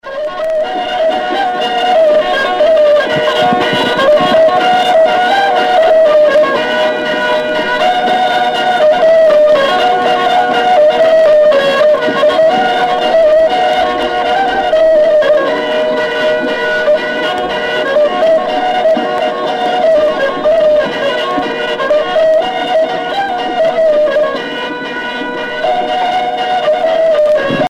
danse : polka des bébés ou badoise
Sonneurs de vielle traditionnels en Bretagne
Pièce musicale éditée